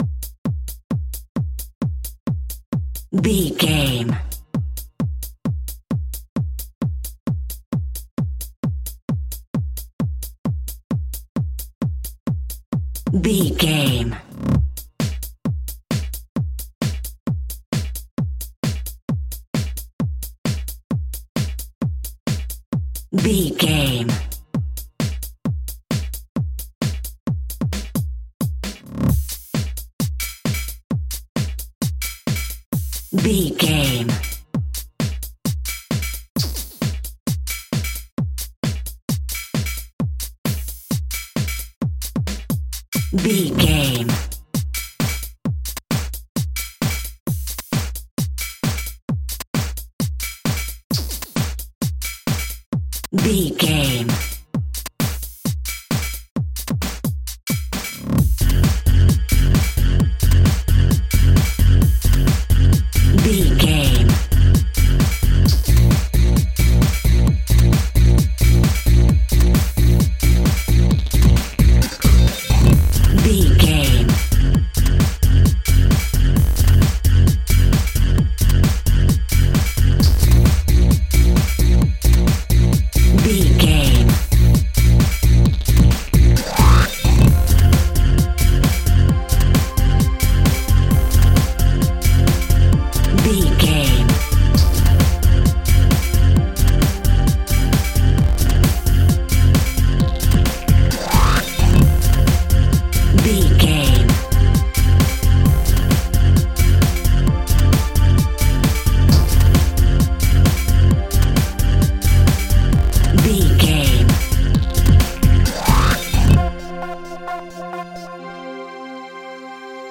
Ionian/Major
aggressive
powerful
dark
driving
energetic
intense
drums
synthesiser
drum machine
house
techno
electro house
synth lead
synth bass